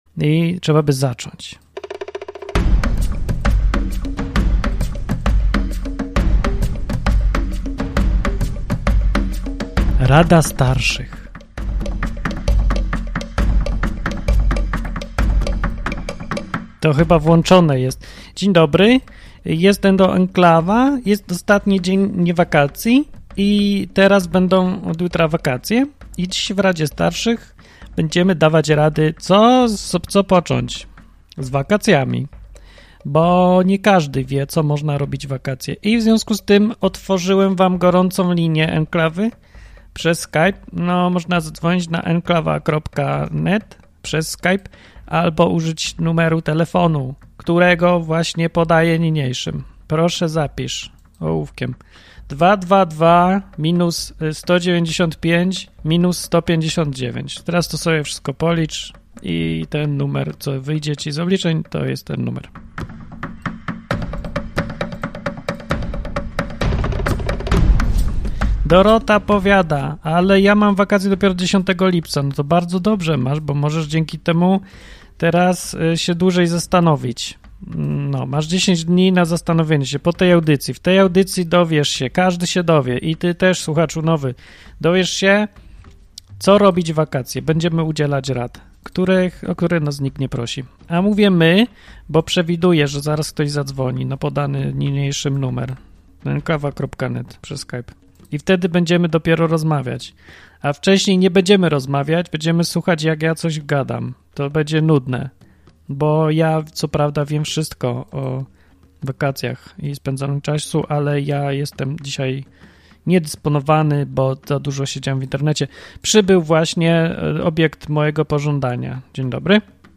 Co tydzień w poniedziałek prowadzący programy w Enklawie zbierają się, aby udzielać słuchaczom rad.